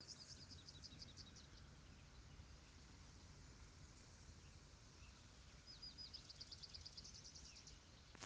Hooded Siskin (Spinus magellanicus)
Sex: Male
Life Stage: Adult
Country: Argentina
Location or protected area: Florencio Varela
Condition: Wild
Certainty: Photographed, Recorded vocal